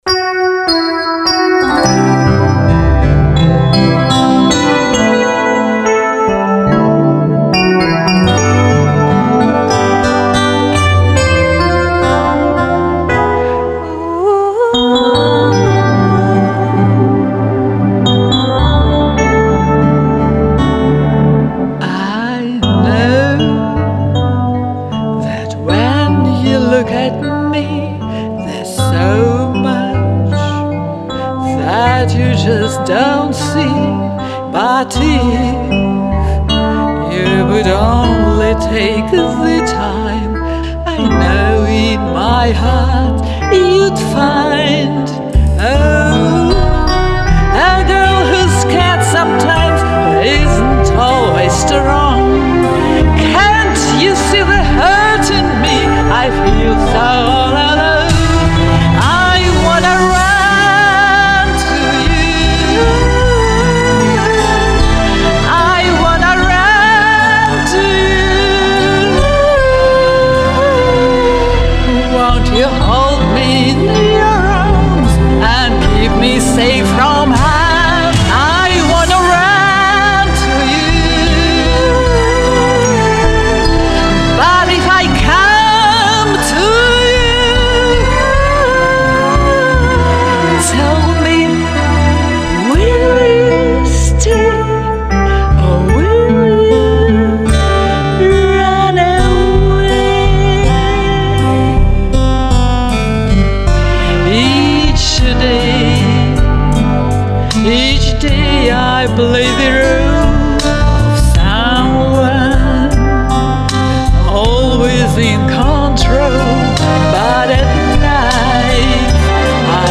Обе соперницы спели шикарно!